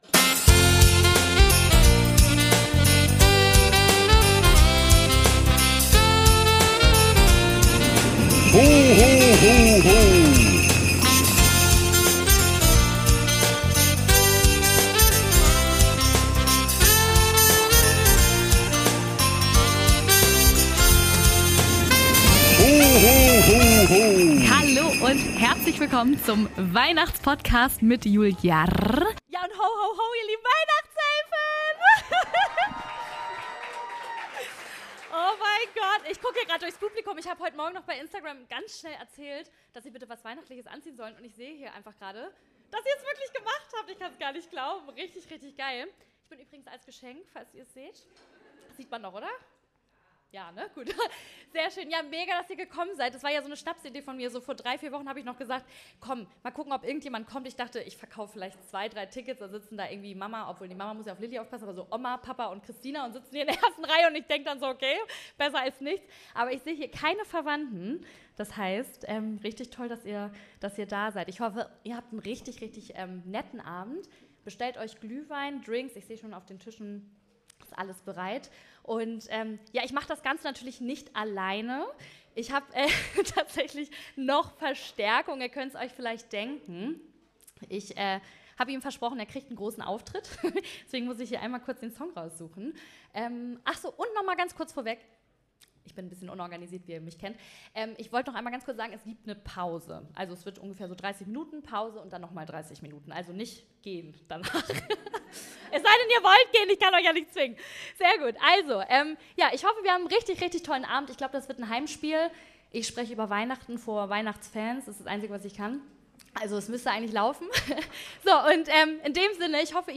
Ich durfte den Podcast vor einigen Weihnachtselfen aufnehmen. Unser erstes Live Event!
Die Qualität ist natürlich nicht so wie ihr es sonst gewohnt seid, aber trotzdem sehr gut. Also wenn ihr die Top 3 der schlimmsten Geschenke zu Weihnachten von mir erfahren, oder auch im Gegenzug die schönste Geschenke hören wollt- Hört in die Folge rein!